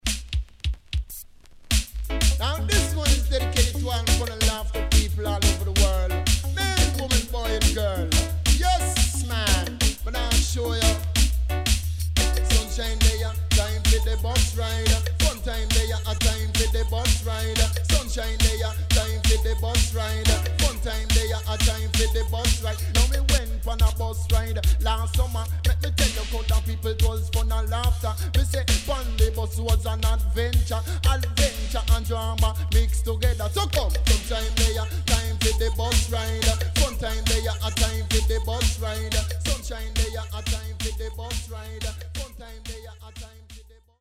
HOME > DISCO45 [DANCEHALL]
SIDE A:盤質は良好です。